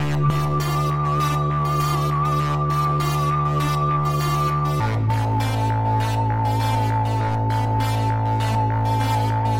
标签： 100 bpm Weird Loops Synth Loops 1.62 MB wav Key : Unknown
声道立体声